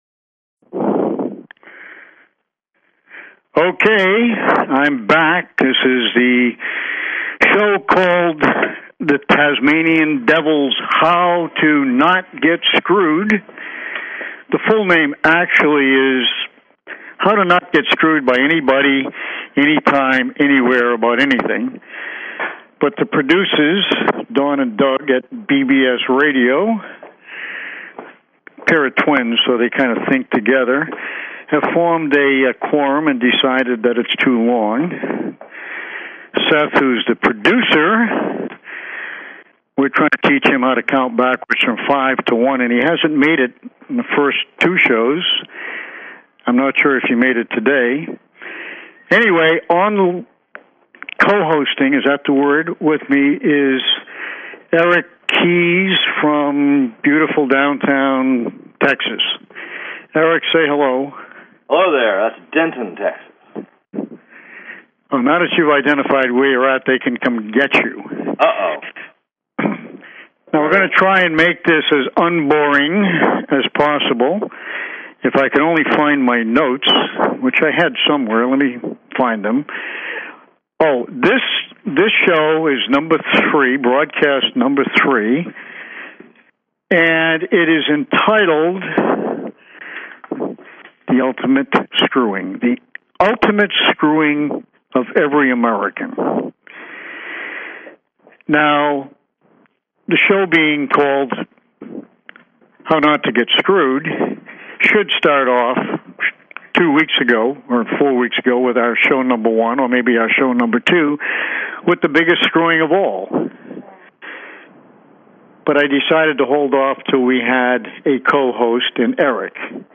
Talk Show Episode, Audio Podcast, How_To_NOT_Get_Screwed and Courtesy of BBS Radio on , show guests , about , categorized as
This is a show of talk, NO music…NO music in the background, the foreground, the ground next door, and at the front or back of the show – NO BLOODY MUSIC.